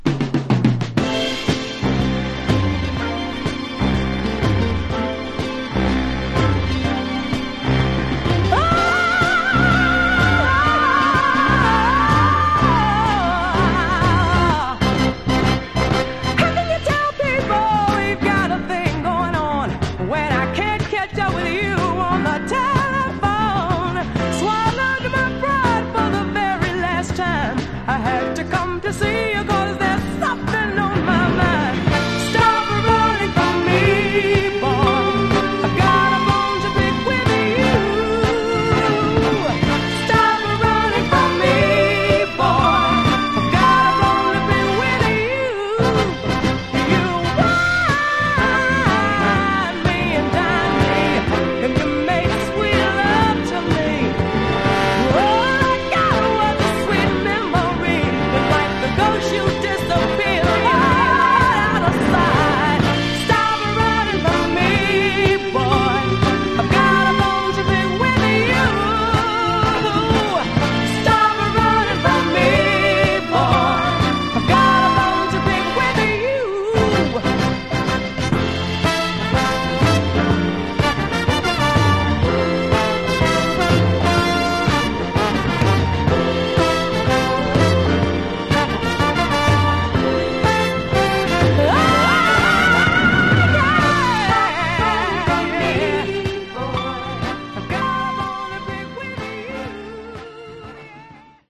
Genre: Other Northern Soul
It's a Northern Soul-style dancer you won't want to miss.